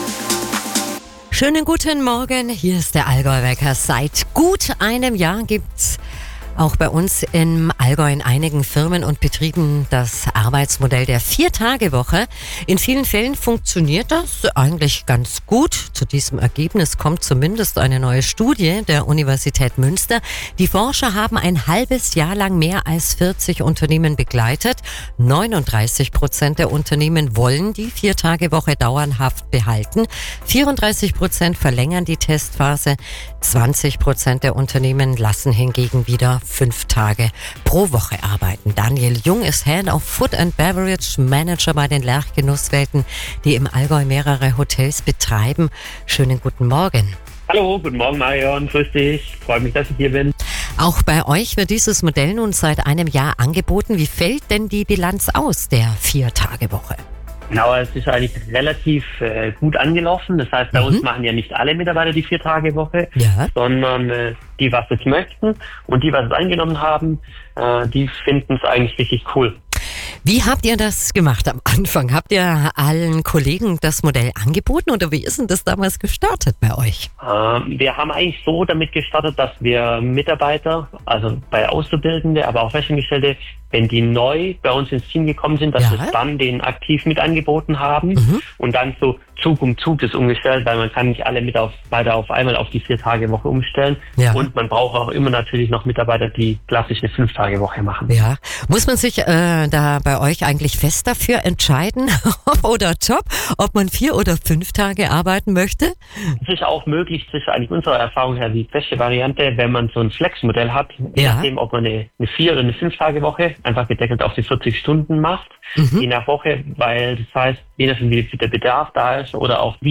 02. Juni 2025: Die Lerch Genusswelten waren zu Gast bei Radio AllgäuHit.